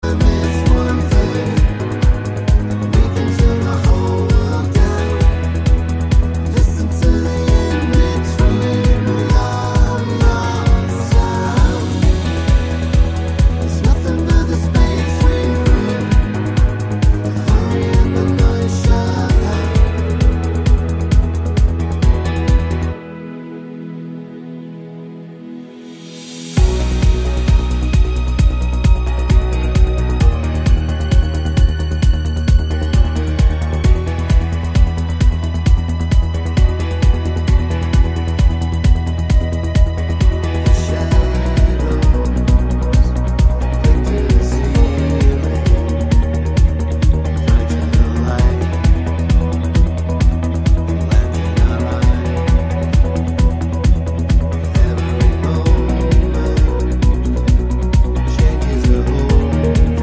No Verse Backing Vocals Pop